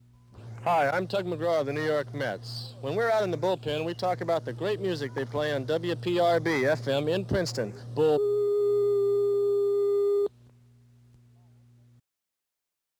Tug McGraw Station ID
Ladies and gentlemen, I give you foul-mouthed champion of great community radio, the legendary Tug McGraw.